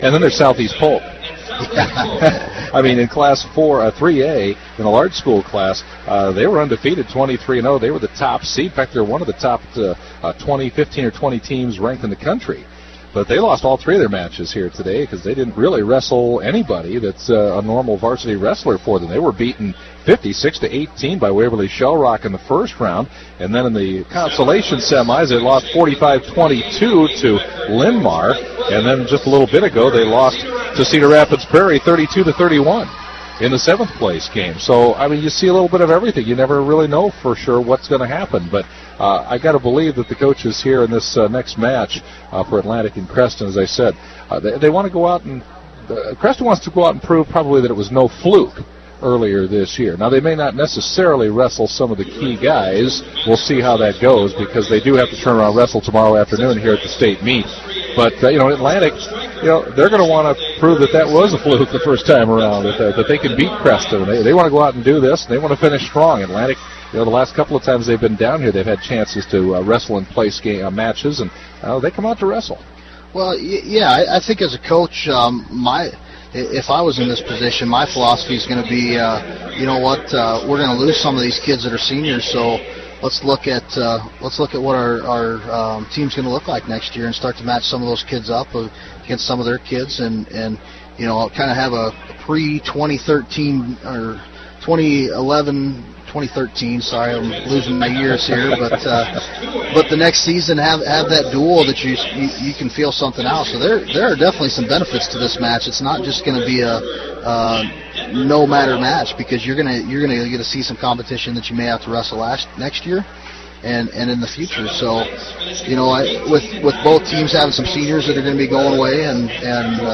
2A State Wrestling Duals: 3rd Place Dual Atlantic vs. Creston O-M